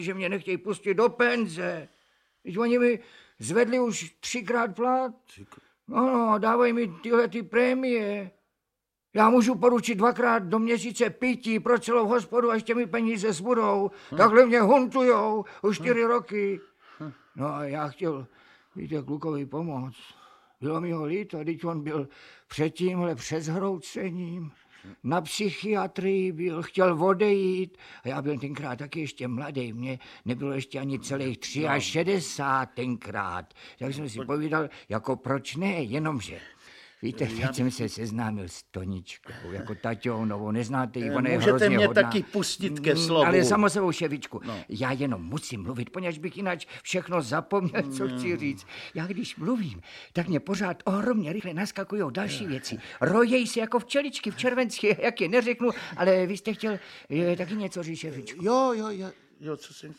Audiobook
Read: Libuše Švormová